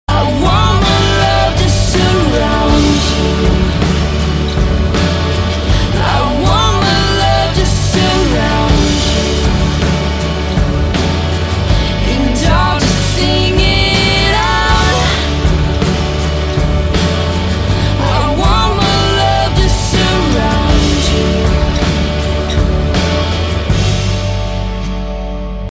M4R铃声, MP3铃声, 欧美歌曲 120 首发日期：2018-05-15 16:16 星期二